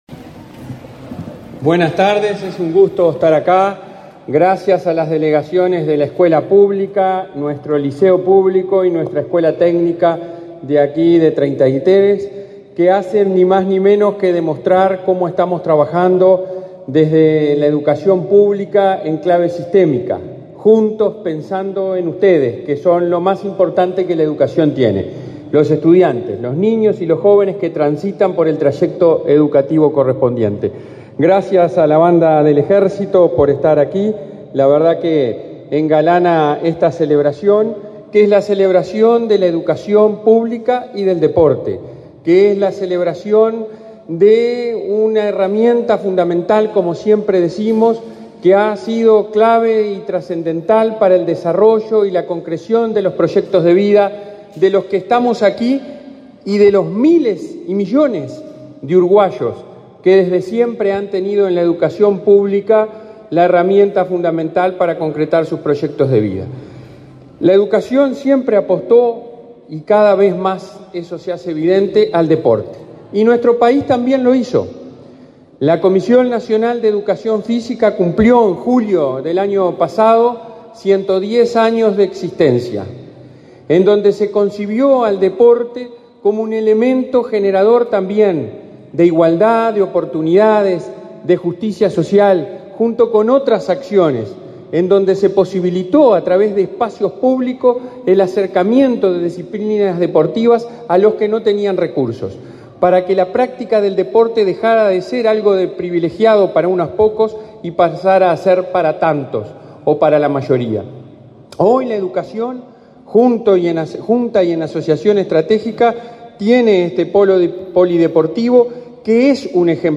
Palabras del presidente de la ANEP, Robert Silva